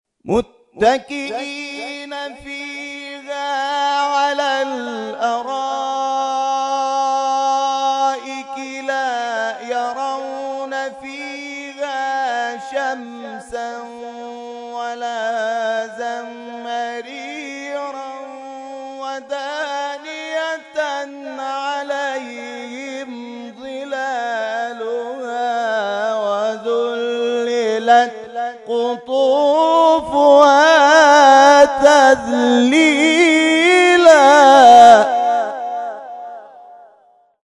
در ادامه قطعات تلاوت این کرسی ها ارائه می‌شود.